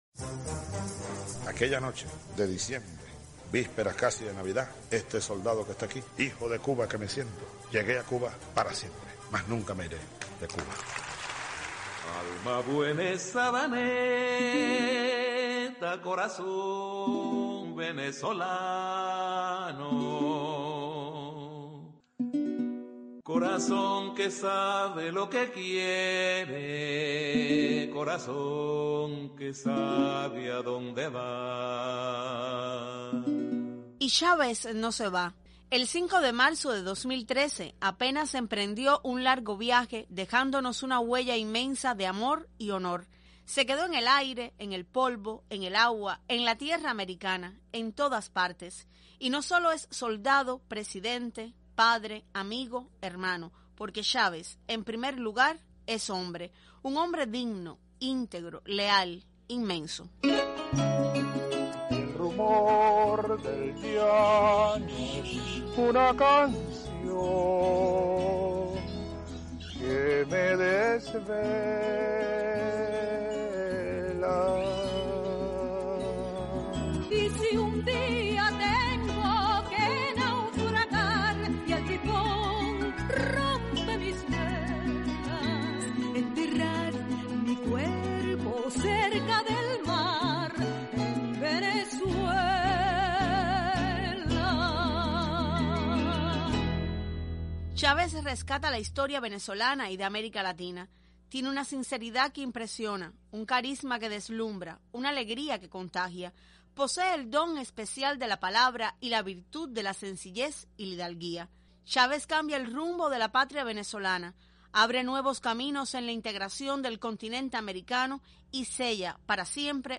cronica_chavez.mp3